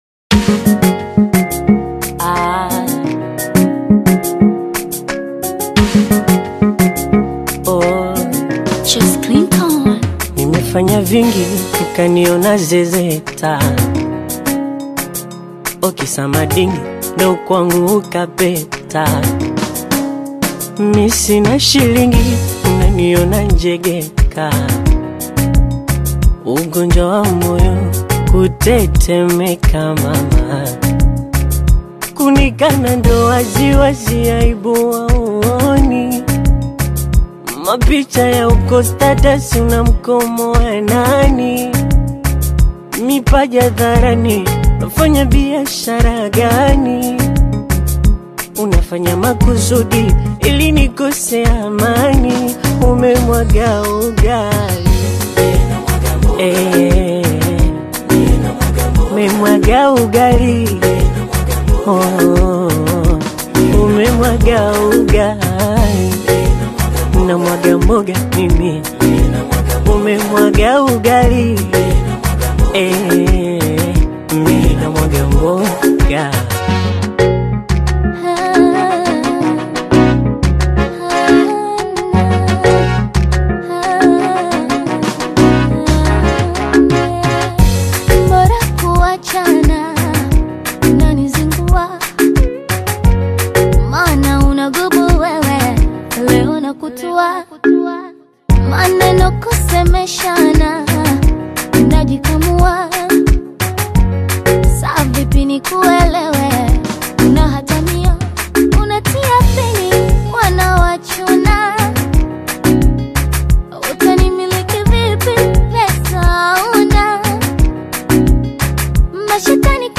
vibrant Bongo Flava collaboration
rhythmic melodies and meaningful lyrics
Genre: Bongo Flava